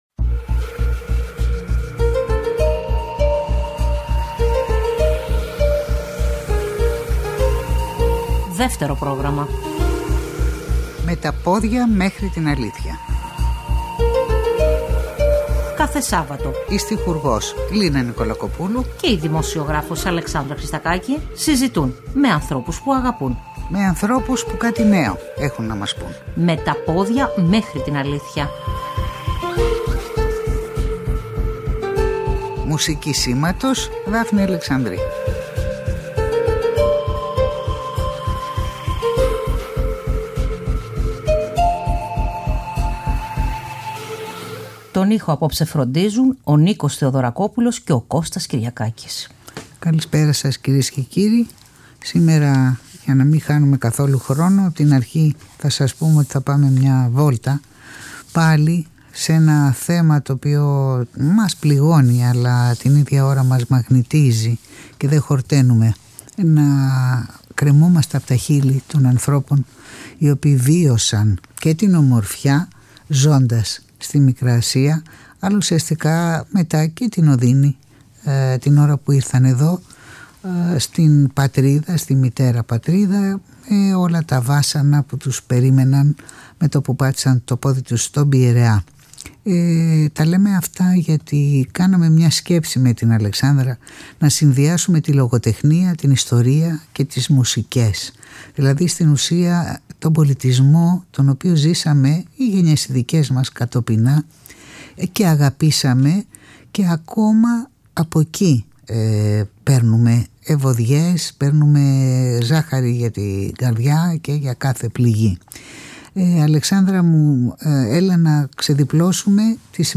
Η ηθοποιός Μάνια Παπαδημητρίου διαβάζει αποσπάσματα από το βιβλίο της Λένας Διβάνη «Ονειρεύτηκα την Διδώ» εκδόσεις Πατάκη.
παίζουν και τραγουδούν σμυρναίικα τραγούδια.